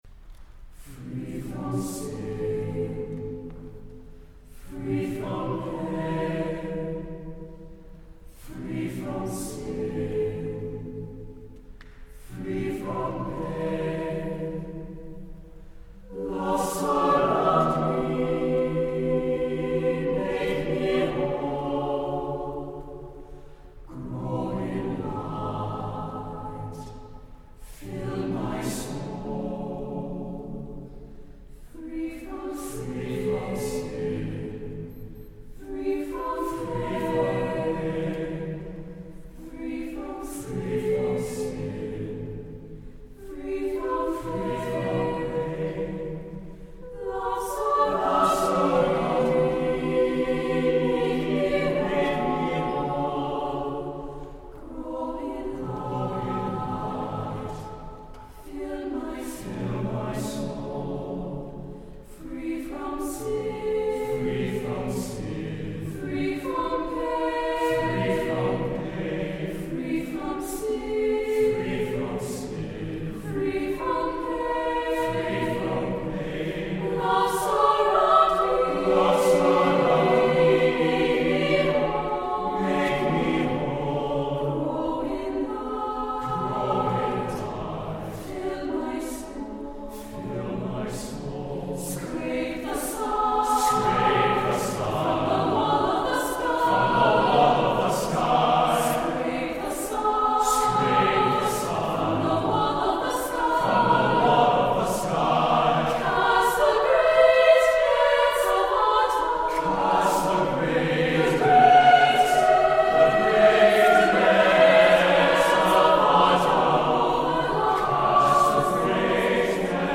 Composer: Choral
Level: SATB a cappella